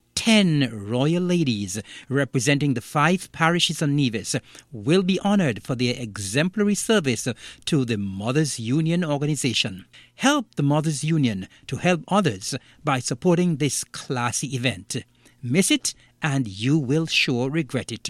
Nevis’ Deputy Premier, Hon. Eric Evelyn shared more on the event: